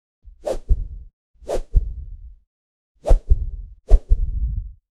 Camera-effects Sound Effects - Free AI Generator & Downloads
a-windy-medium-fast-whoos-dl3jgzeu.wav